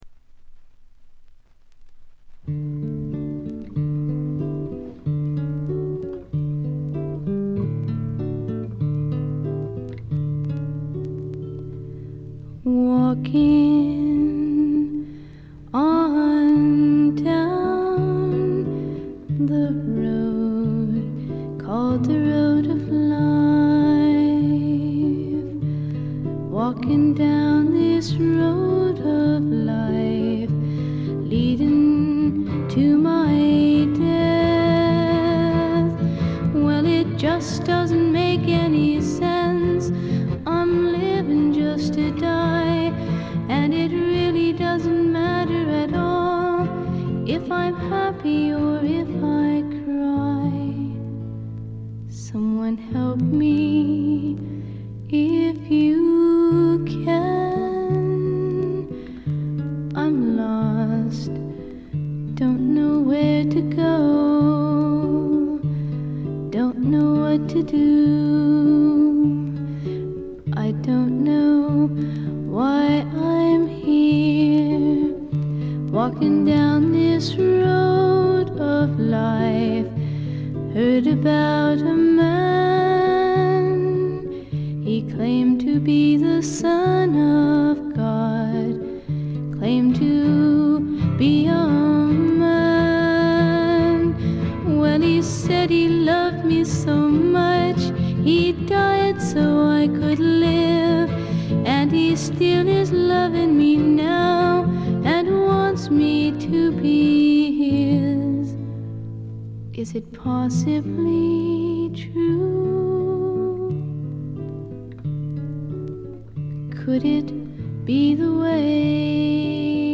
ところどころで軽微なチリプチ。気になるようなノイズはありません。
演奏はほとんどがギターの弾き語りです。
試聴曲は現品からの取り込み音源です。